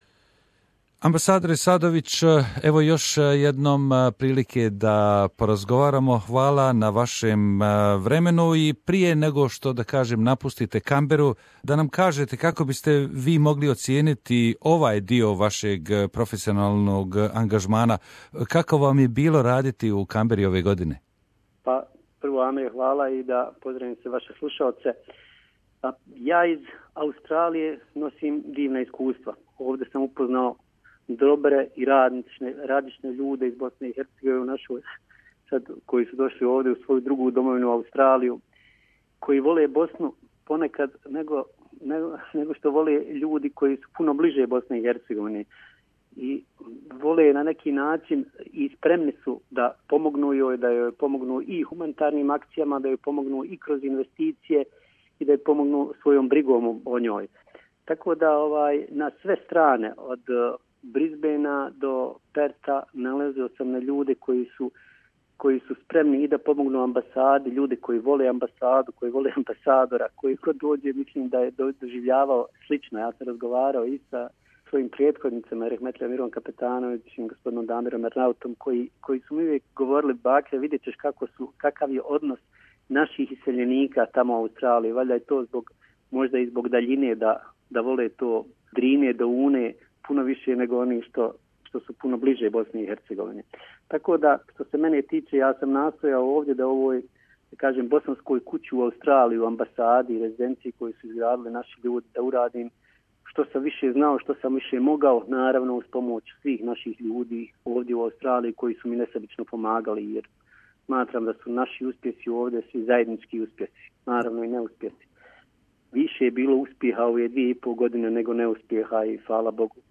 BH Ambassador Bakir Sadovic, Interview